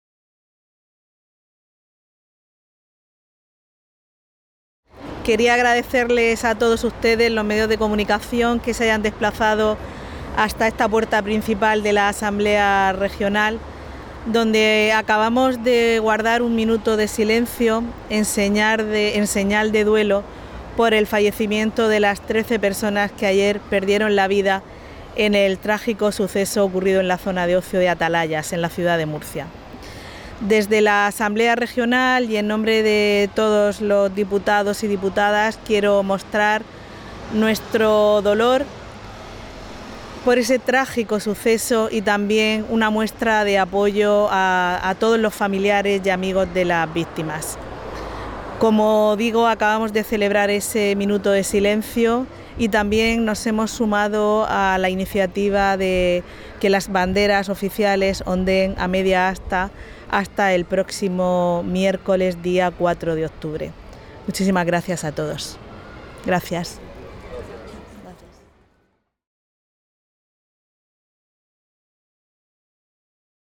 • Declaraciones de la presidenta de la Asamblea Regional, Visitación Martínez